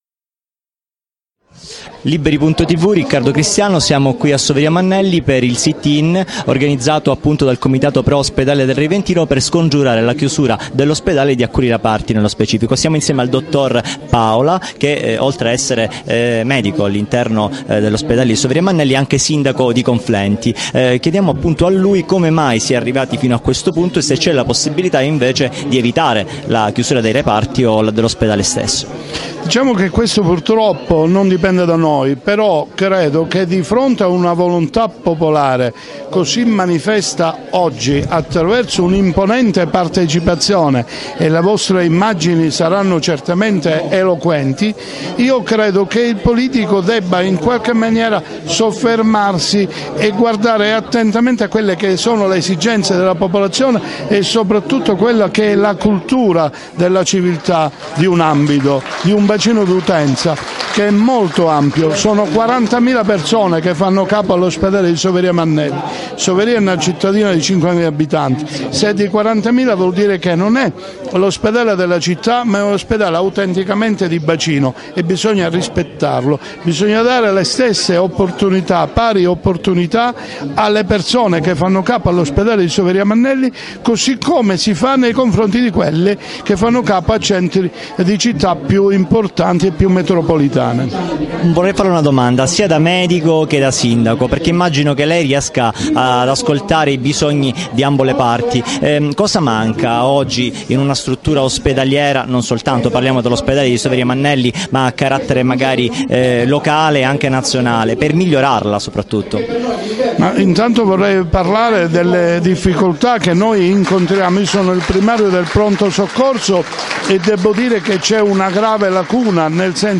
Manifestazione per la salvaguardia dell'Ospedale del Reventino a Soveria Mannelli (CZ), 4 maggio 2015.
Intervista al Dott. Giovanni Paola Sindaco del Comune di Conflenti (CZ).